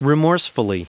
Prononciation du mot remorsefully en anglais (fichier audio)
Prononciation du mot : remorsefully